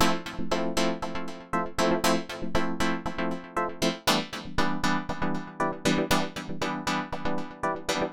28 Chords PT3.wav